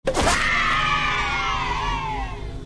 Grunt Birthday Party - MP3 Download
Grunt-Birthday-Party.mp3